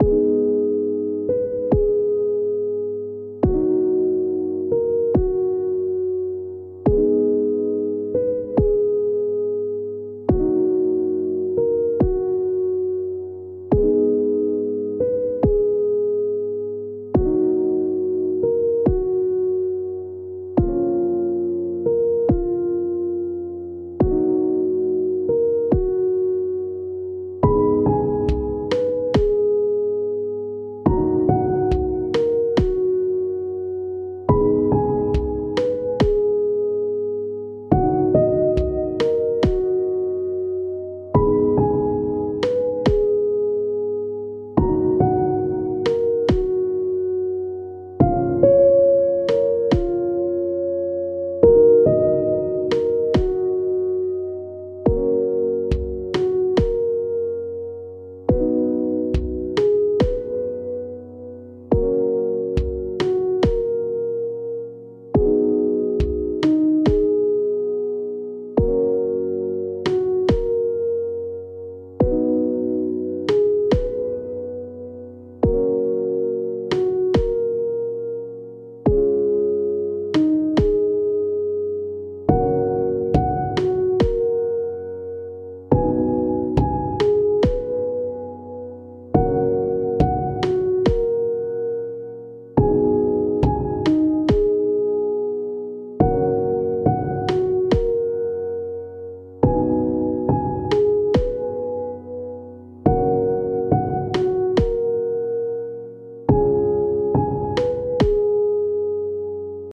チルな印象の一曲になりました！
ループ：◎
BPM：70 キー：F# ジャンル：ゆったり、おしゃれ 楽器：ピアノ、アンビエント